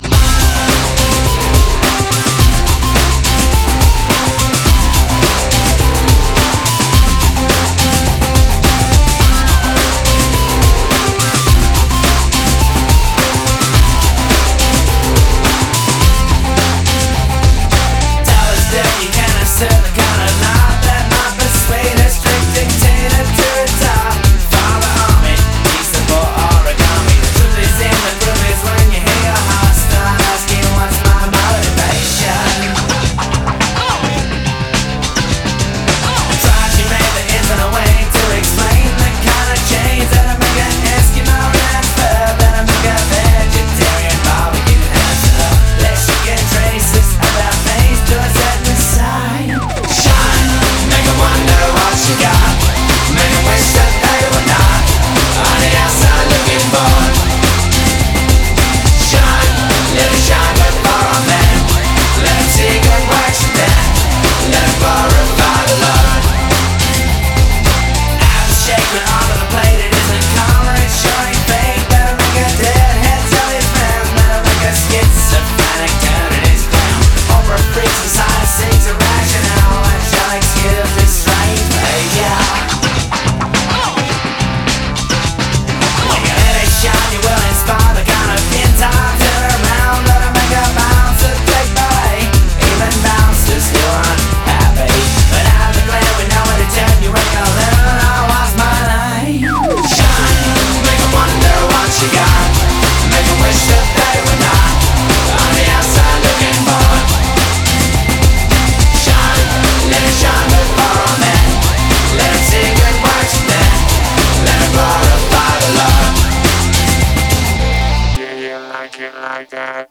BPM106
Audio QualityCut From Video